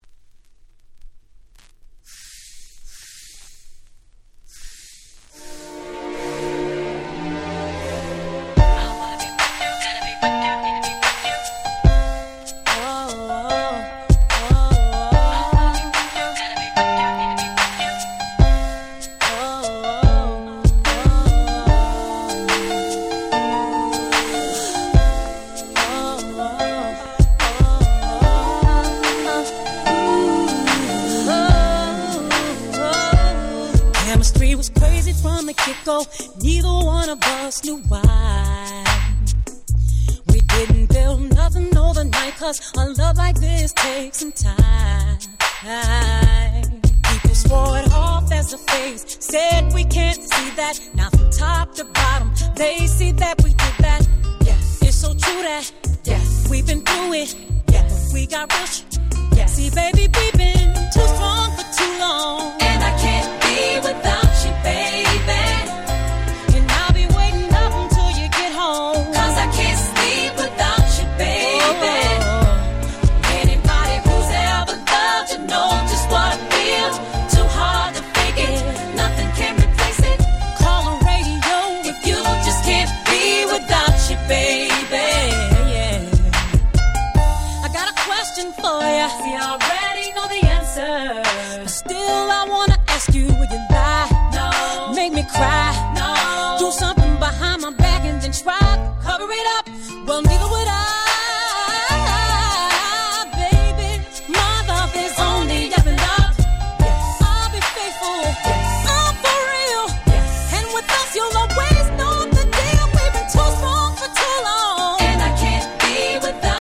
05' Super Nice R&B !!